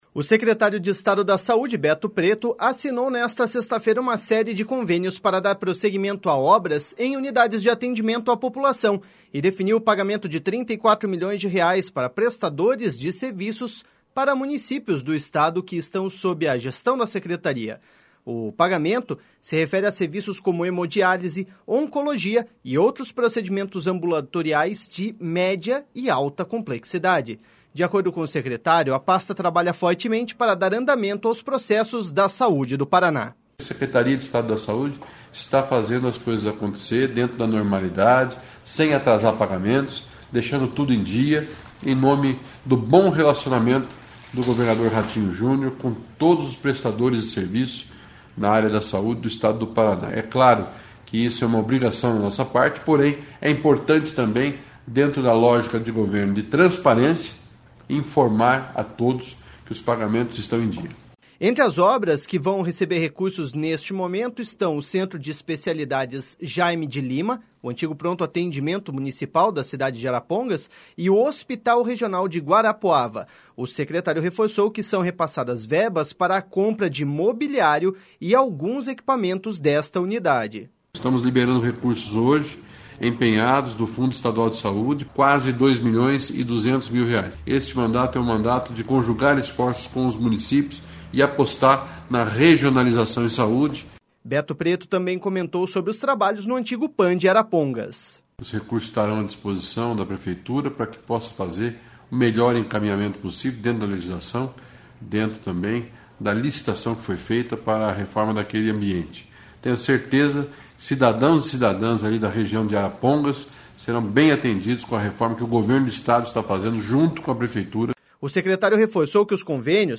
Beto Preto também comentou sobre os trabalhos no antigo PAM de Arapongas.// SONORA BETO PRETO.//